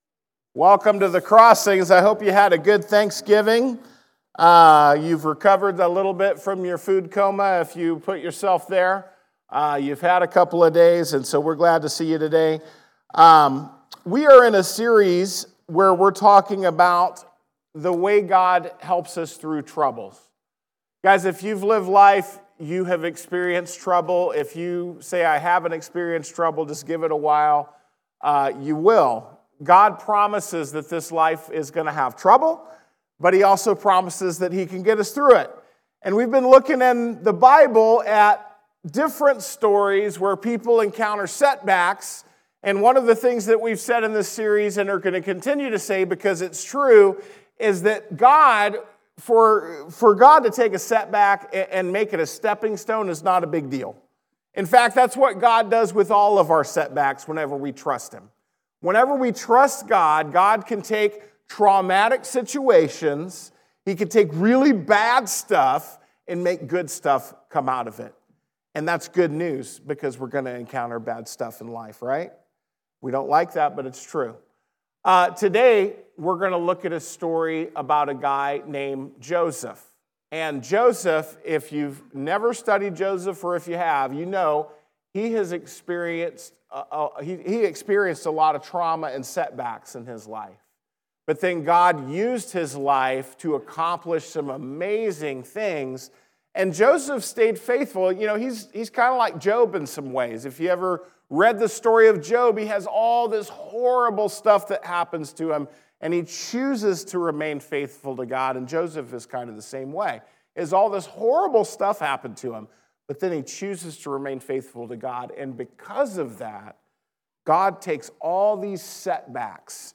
Lesson presented at The Crossings Church Collinsville in Collinsville, IL – a non-denominational church that meets Sunday mornings at 2002 Mall Street, Collinsville, IL just outside of St. Louis.